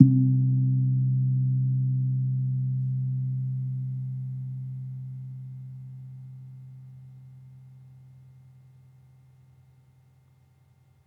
Gong-C2-f.wav